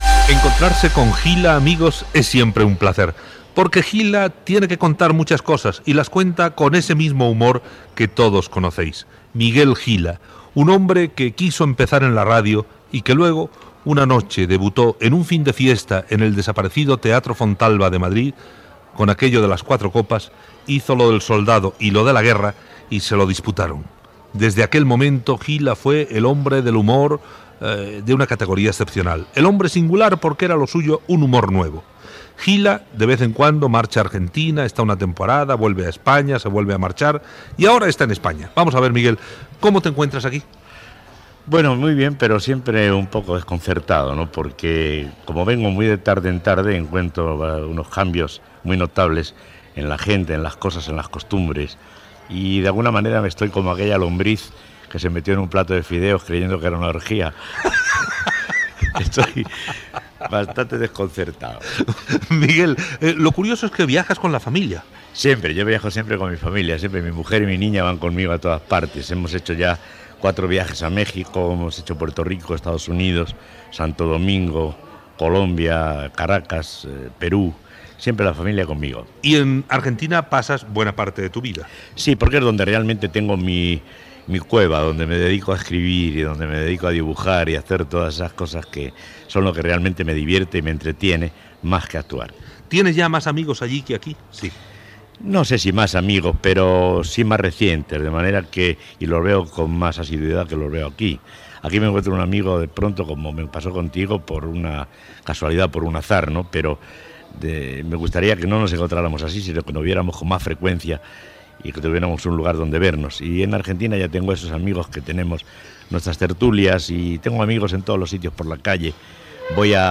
Presentació i entrevista al dibuixant i actor Miguel Gila. S'hi parla sobre els seus viatges, les seves estades a Espanya i Argentina, els horaris de les actuacions a Espanya...
Entreteniment